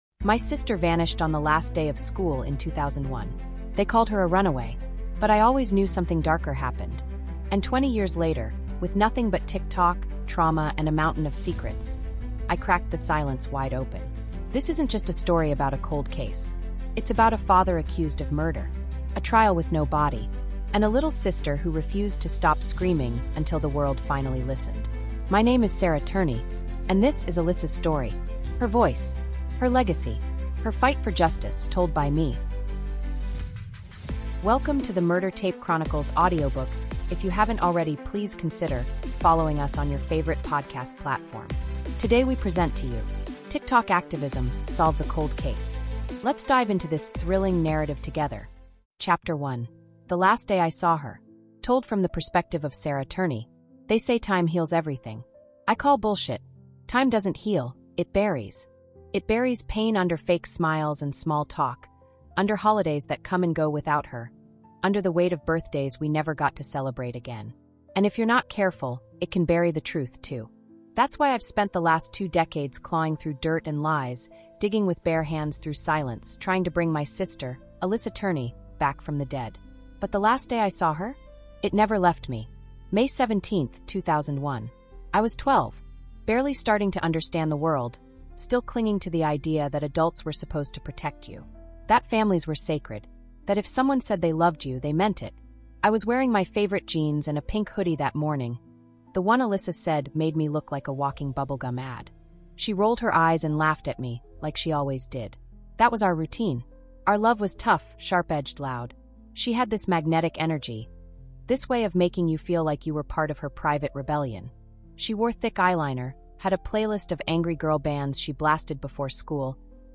TikTok Activism Solves A Cold Case | Audiobook